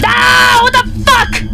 Worms speechbanks
Grenade.wav